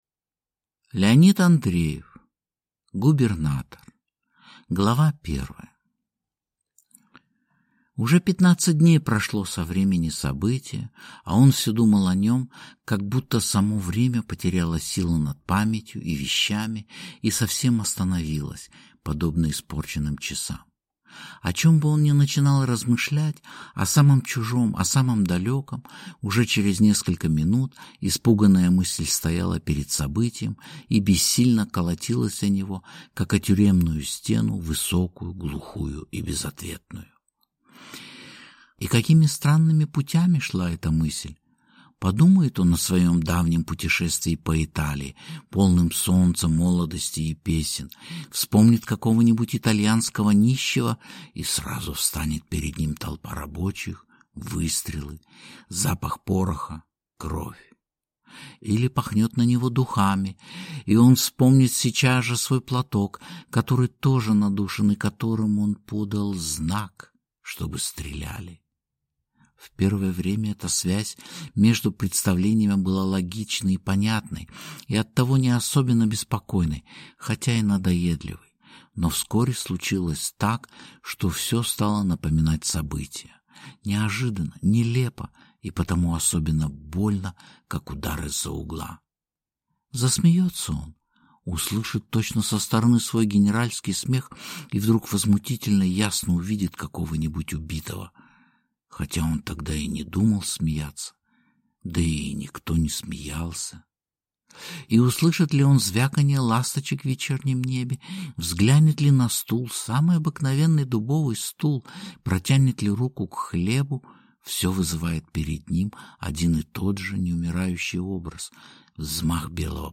Аудиокнига Губернатор | Библиотека аудиокниг